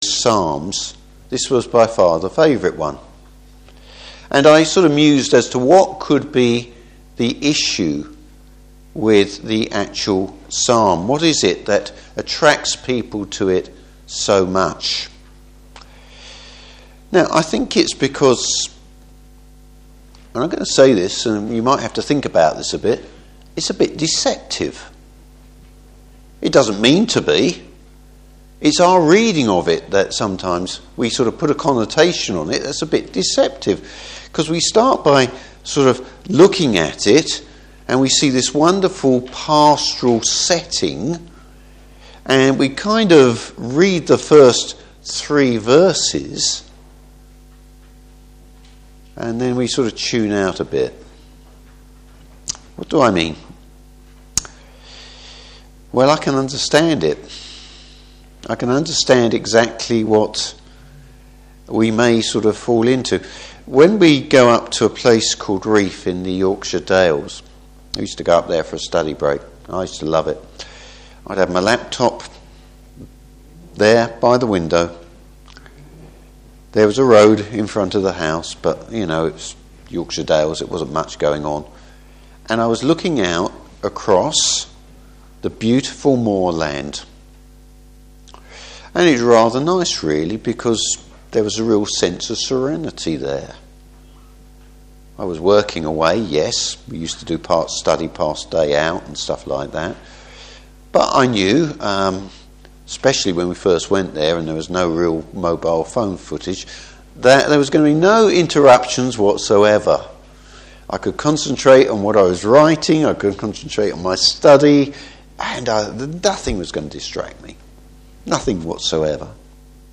Service Type: Evening Service David’s amazement at the Lord’s continual love and care.